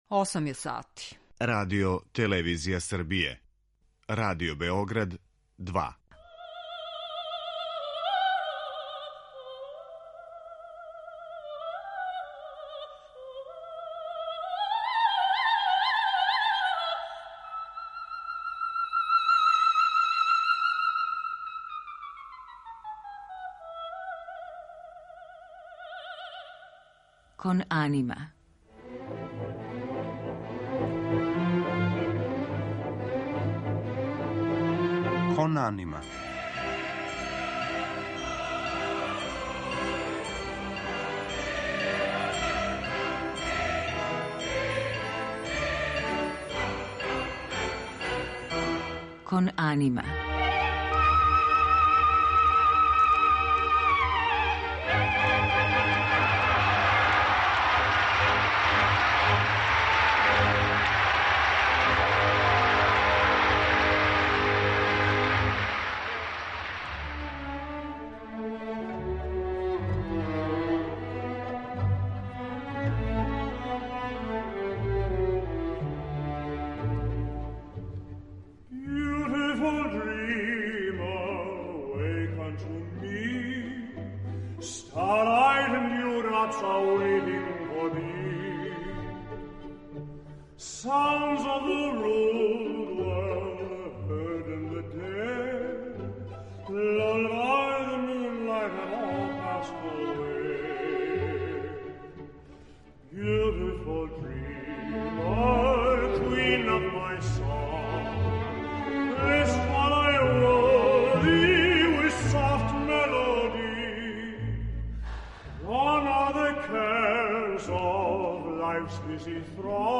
Рaзговор са Томасом Хемпсоном
Један од водећих баритона света, Томас Хемпсон, приликом свог недавног гостовања у Београду, где је одржао концерт у оквиру БЕМУС-а, дао је ексклузивни интервју за Радио Београд 2, који можете слушати у данашњој емисији Кон анима.